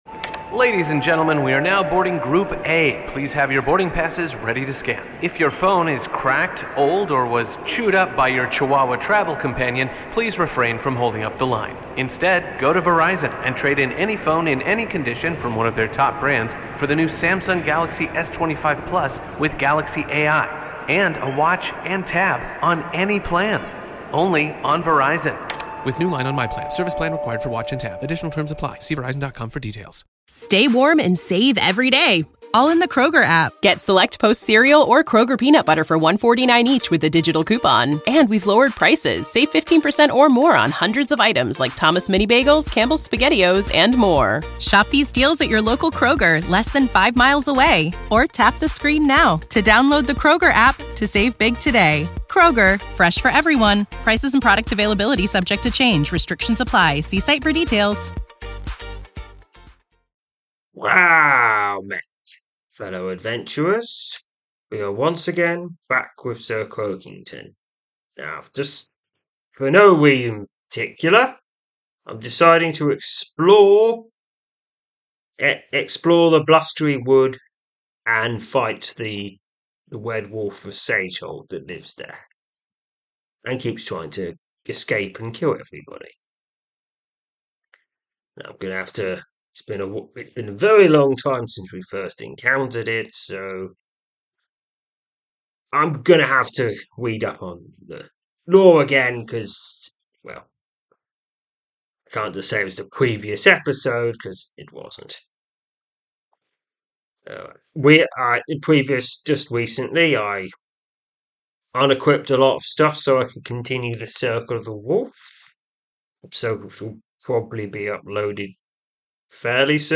I read out and play a selection of adventures from the the fantasy browser RPG Sryth.
Audio Drama